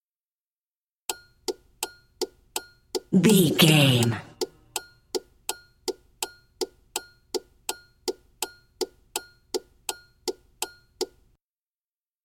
Car turn signal
Sound Effects